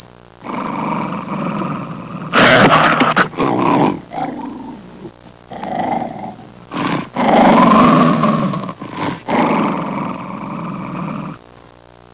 Angry Dog Sound Effect
Download a high-quality angry dog sound effect.
angry-dog.mp3